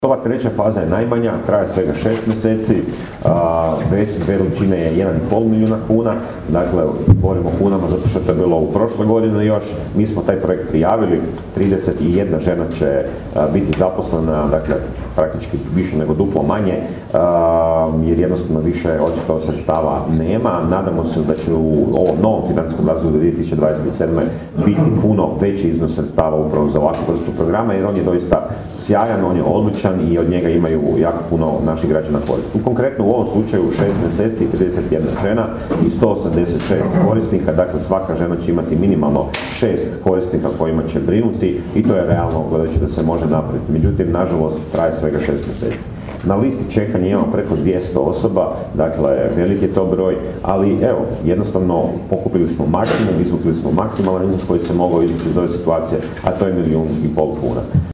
Više na temu projekta rekao je bjelovarski gradonačelnik Dario Hrebak: